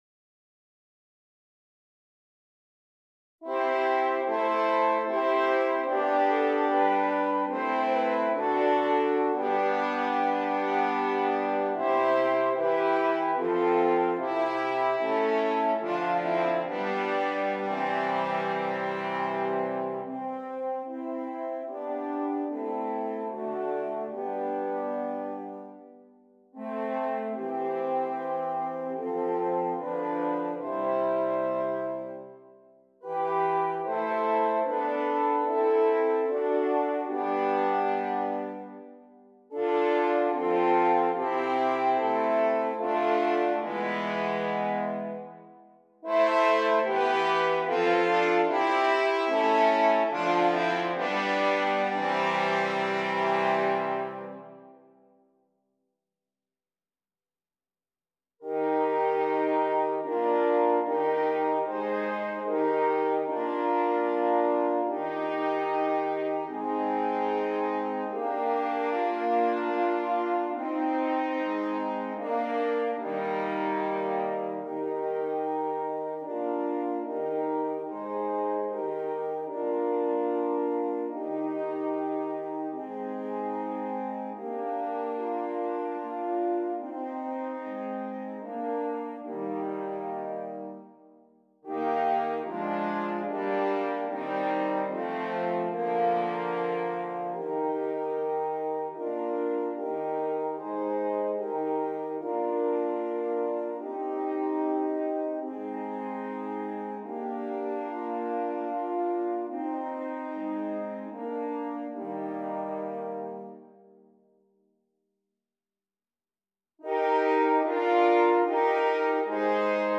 4 F Horns